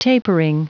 Prononciation du mot tapering en anglais (fichier audio)
Prononciation du mot : tapering